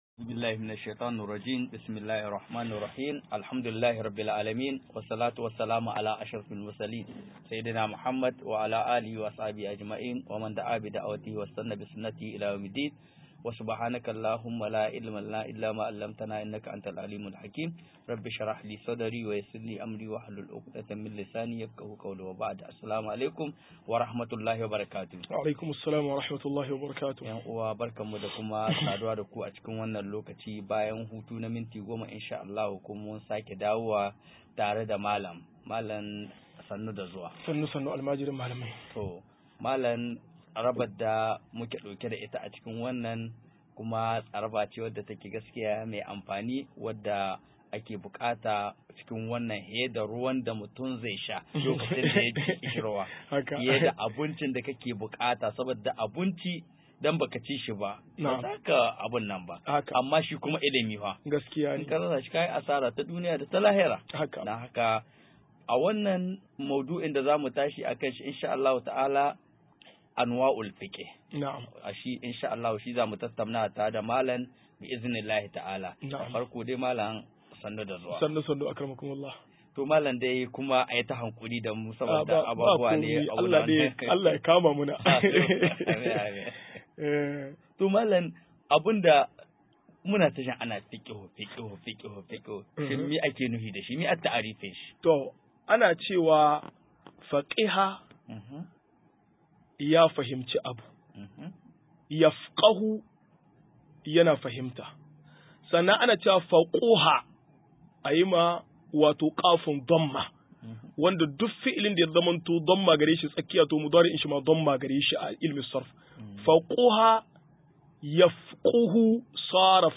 186-Nau i Nua i Na Fikihu - MUHADARA